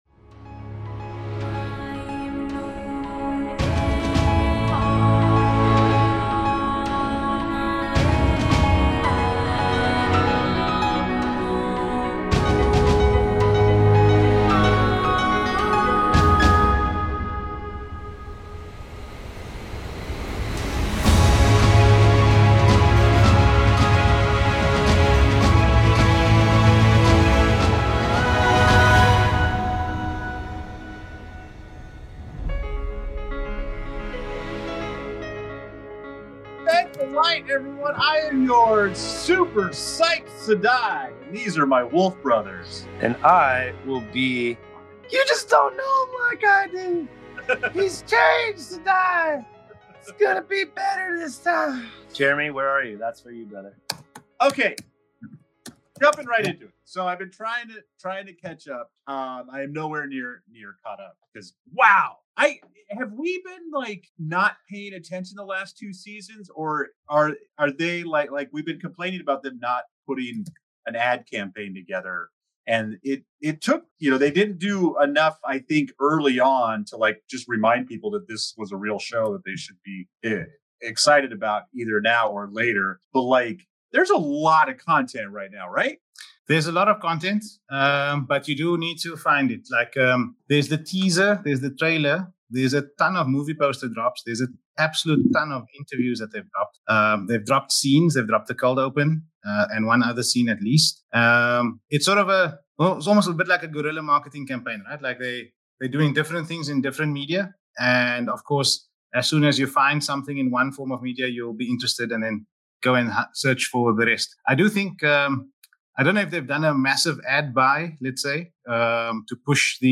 In this episode, our hosts dive headfirst into the whirlwind of excitement surrounding the upcoming Season Three of "The Wheel of Time."
As they dissect the intricacies of the narrative and character arcs, our hosts engage in spirited debates about the editing choices made in previous seasons and their implications for the story ahead. Expect plenty of laughter, insights, and a dash of speculation as they navigate the tangled web of plotlines and predictions, all while sharing their love for Robert Jordan's epic saga.